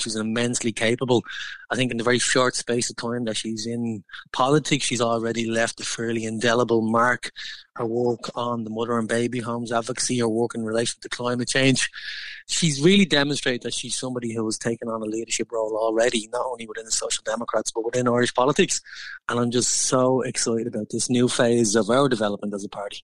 Party colleague, Deputy Gary Gannon says he’s delighted she’ll take on the role: